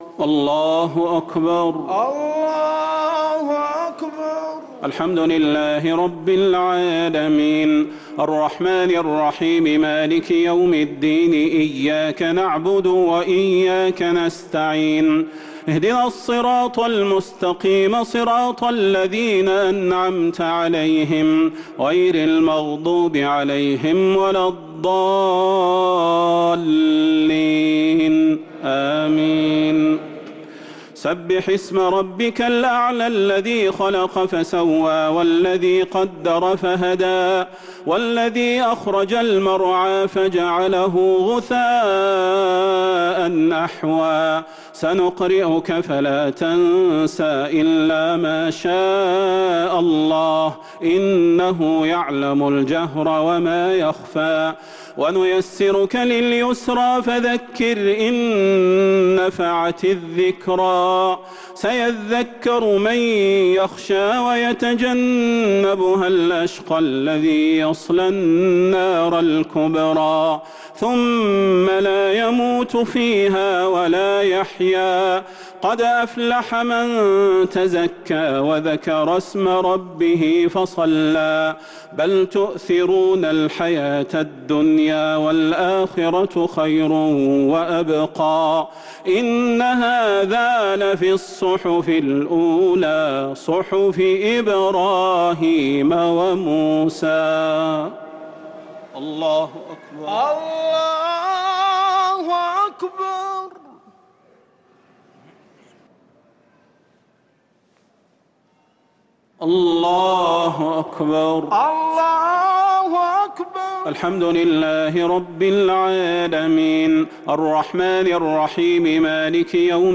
الشفع و الوتر ليلة 11 رمضان 1444هـ | Witr 11st night Ramadan 1444H > تراويح الحرم النبوي عام 1444 🕌 > التراويح - تلاوات الحرمين